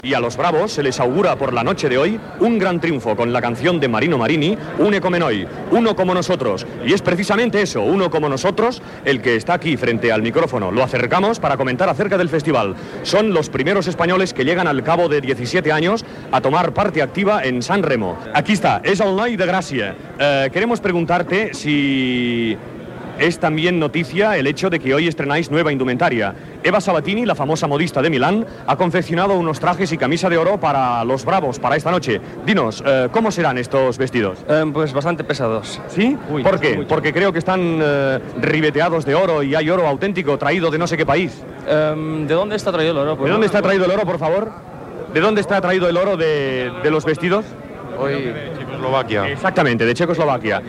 Transmissió del Festival de San Remo
entrevista
Musical